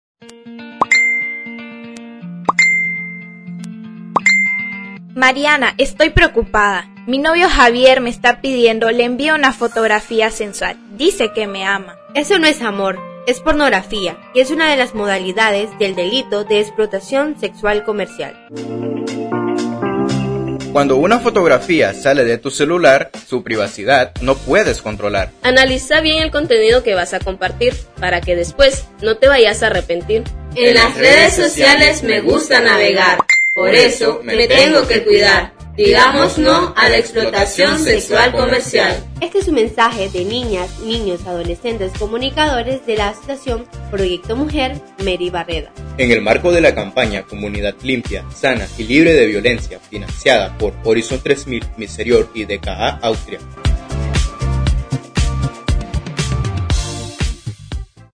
Les compartimos Campaña “Comunidad, Limpia, Sana y Libre de Violencia” ejecutada por niñas, niños y adolescentes comunicadores de la Asociación Mary Barreda en prevención de la violencia basada en género y la Explotación Sexual Comercial.